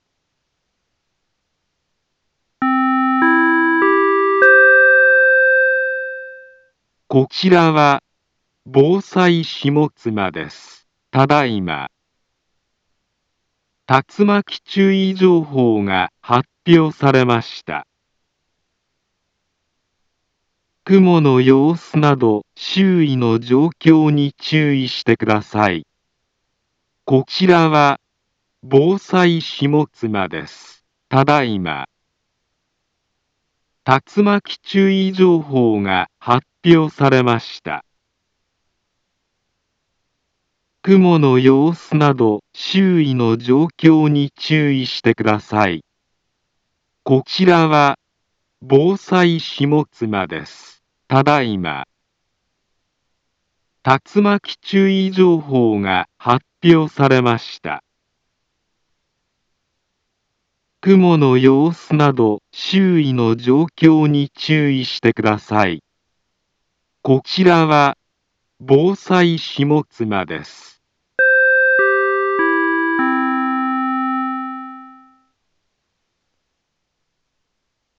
Back Home Ｊアラート情報 音声放送 再生 災害情報 カテゴリ：J-ALERT 登録日時：2023-09-08 13:15:06 インフォメーション：茨城県南部は、竜巻などの激しい突風が発生しやすい気象状況になっています。